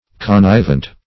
Connivent \Con*niv"ent\, a. [L. connivens, p. pr.]